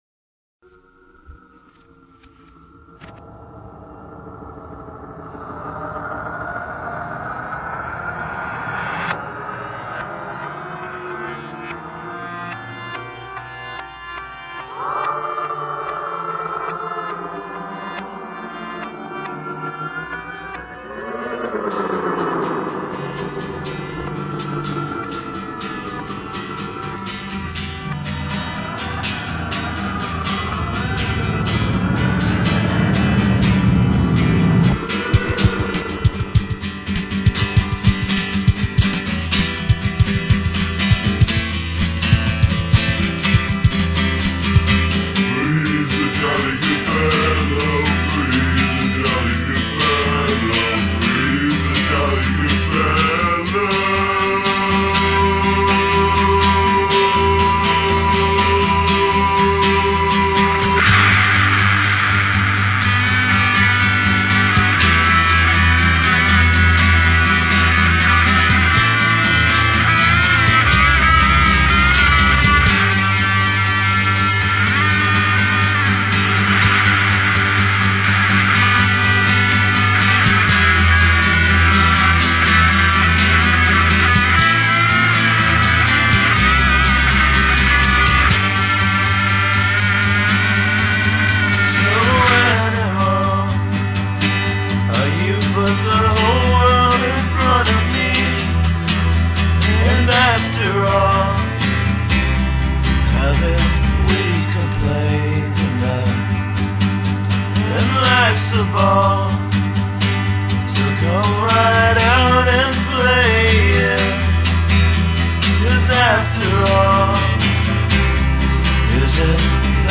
Genre: lo-fi, outsider pop, hometaper